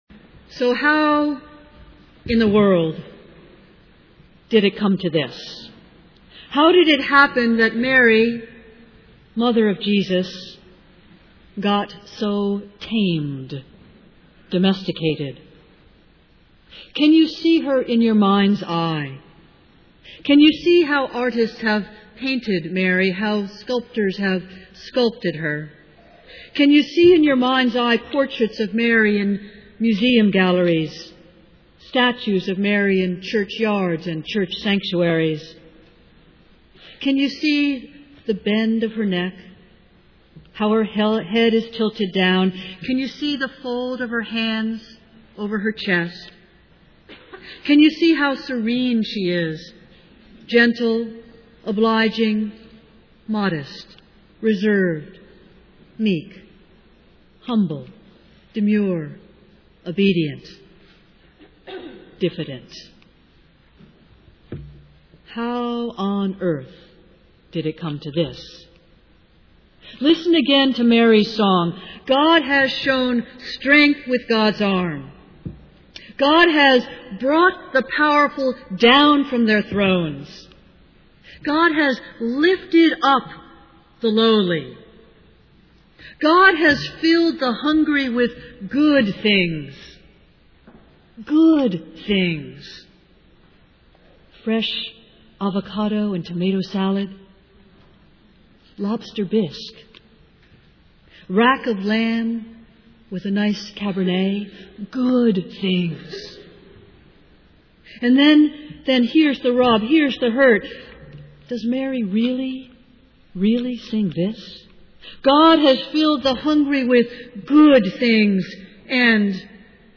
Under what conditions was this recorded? Festival Worship